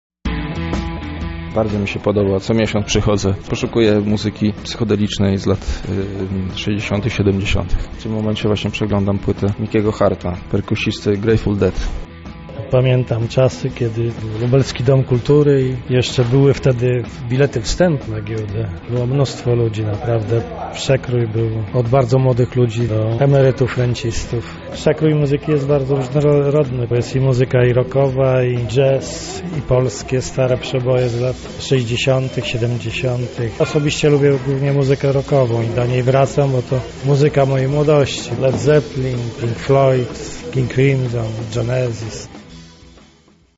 Nasza reporterka zapytała uczestników o wrażenia.